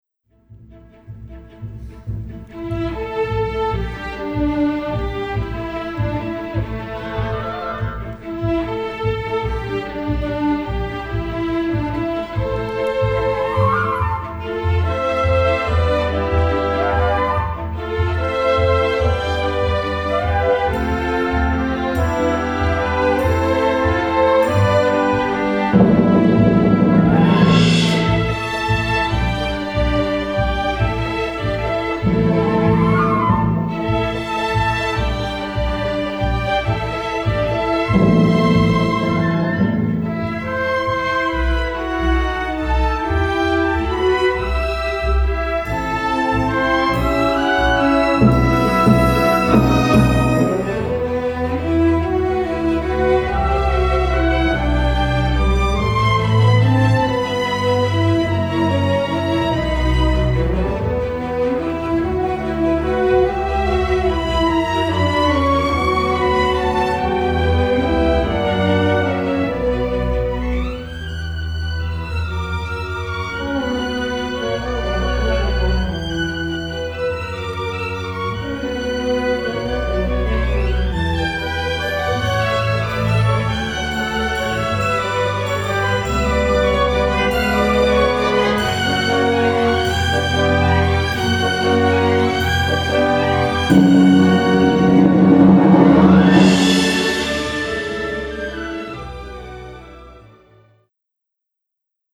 Orchestre Symphonique